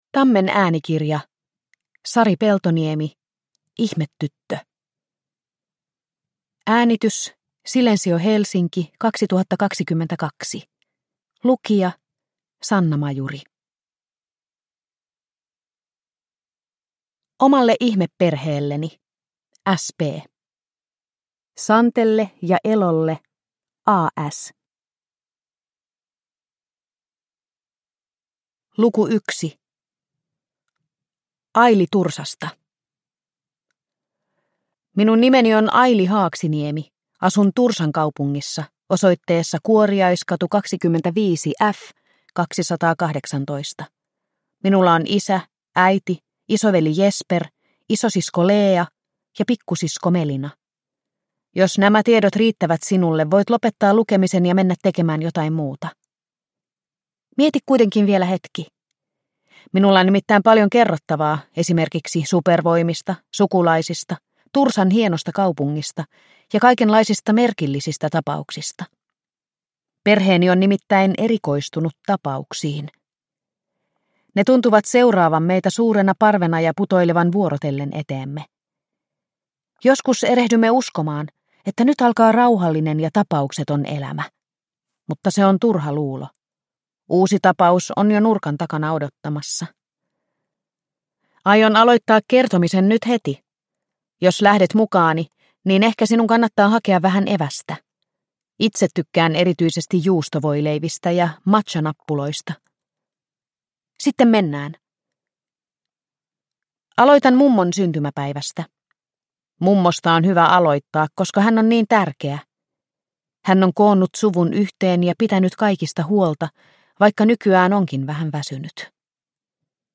Ihmetyttö – Ljudbok – Laddas ner